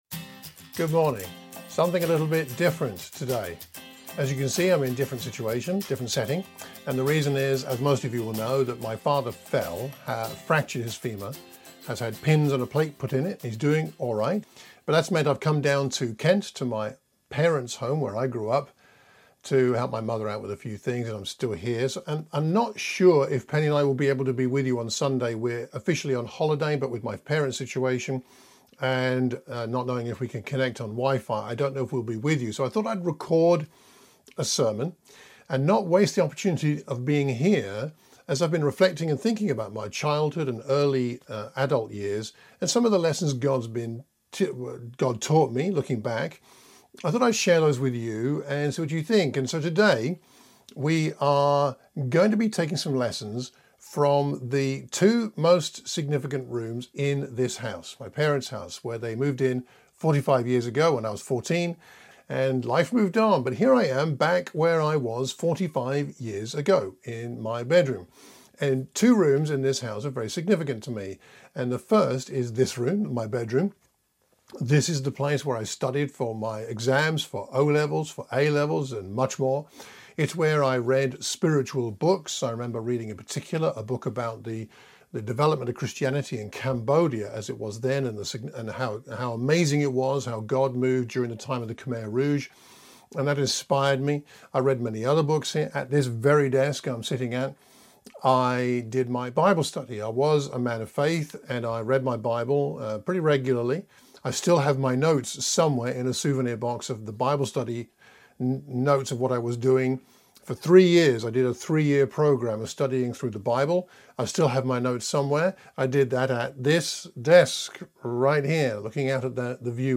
Sermon for the Watford church of Christ, 13 September 2020